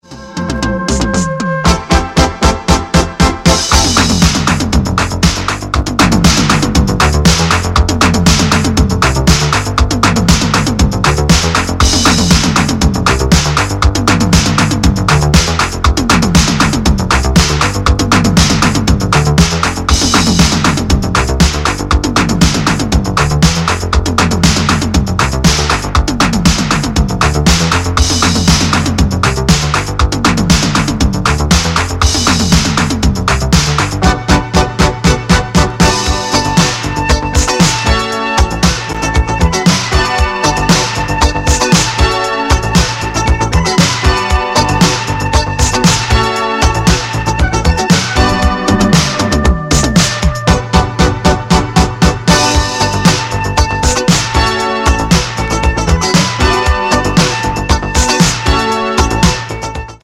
Great boogie track